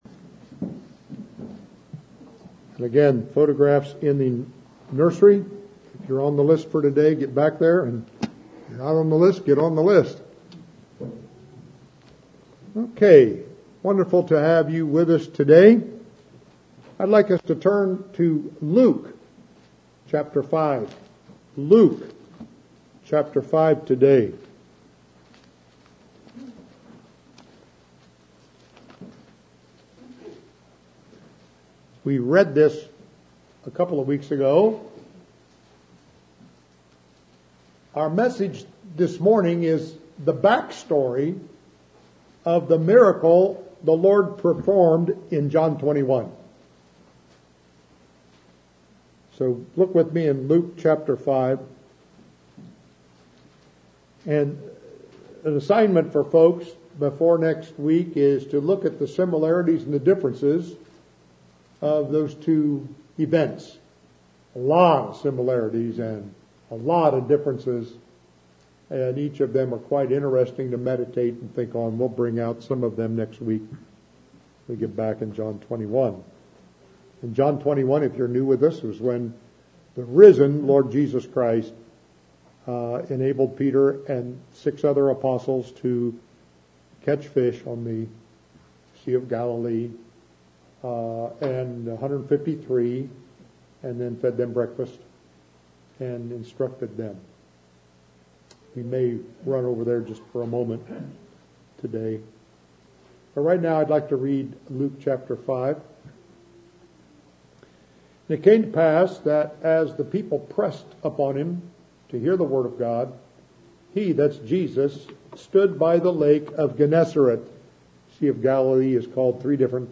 Fishing For Men Audio Sermon